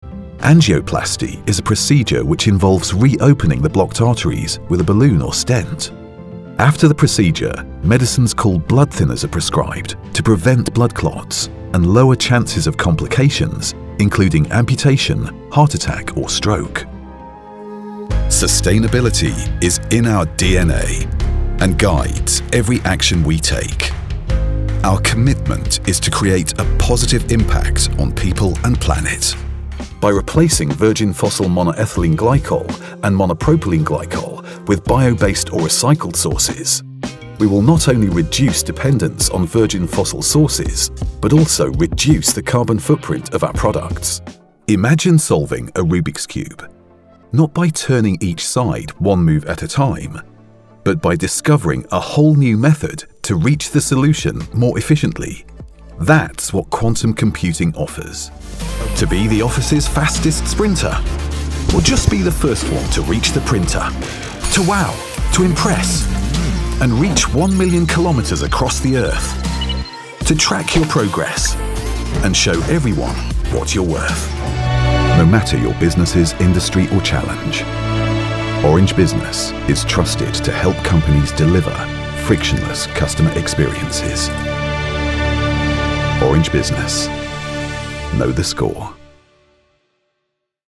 Englisch (Britisch)
Kommerziell, Tief, Natürlich, Unverwechselbar, Zuverlässig
Unternehmensvideo